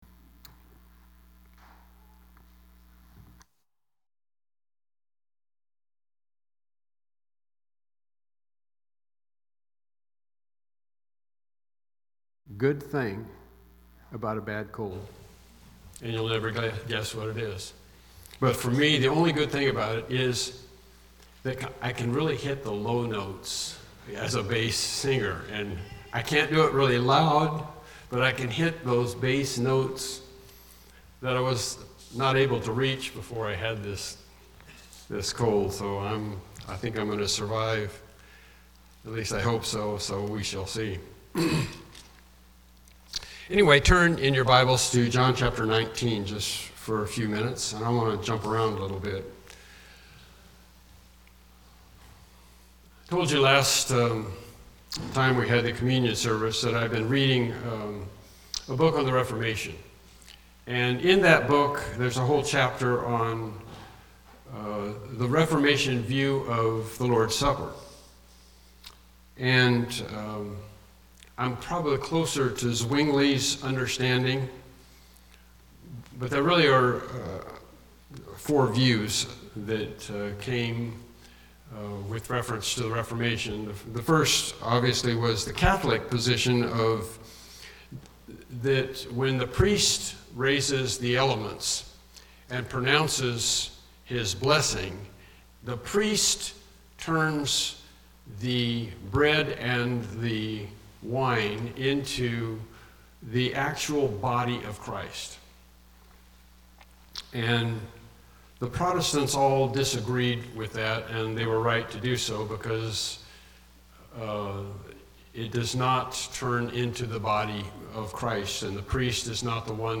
Evening Sermons Passage
28-30 Service Type: Evening Worship Service « The Compromise of the Gospel Lesson 8